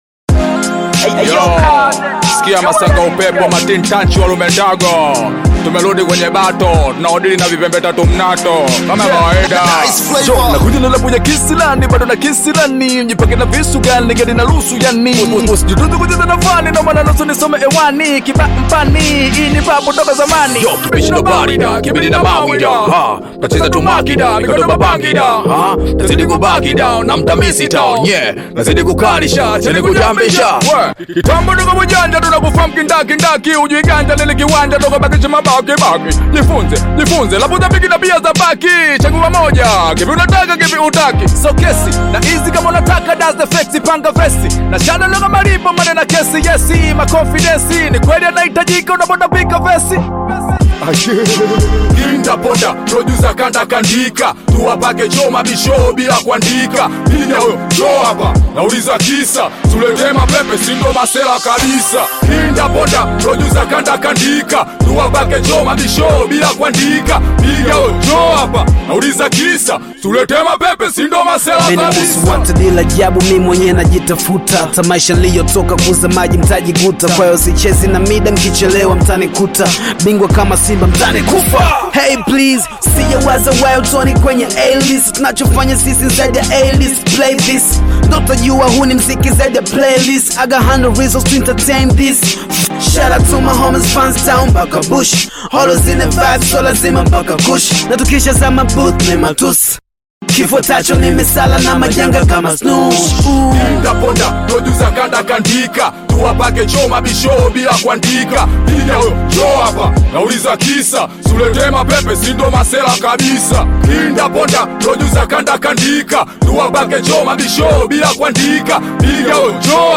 Tanzanian Bongo Flava
a dynamic hip-hop track
African Music